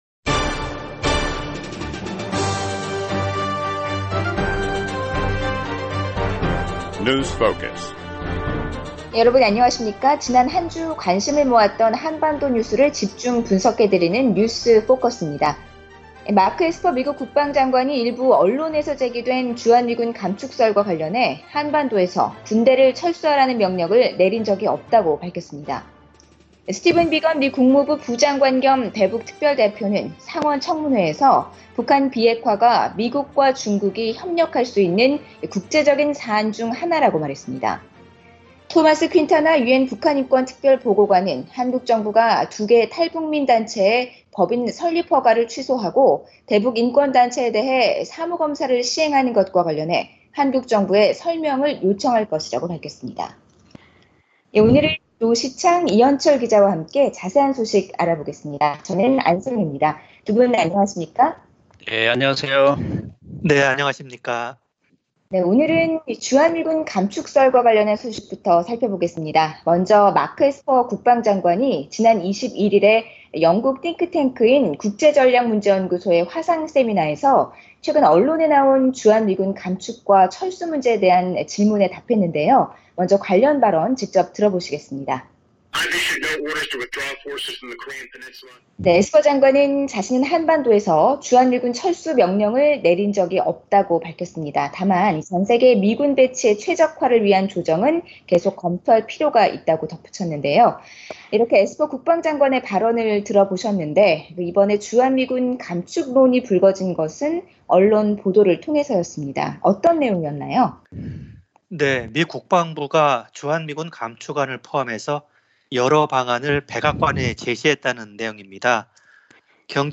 지난 한 주 관심을 모았던 한반도 뉴스를 집중 분석해 드리는 뉴스 포커스입니다.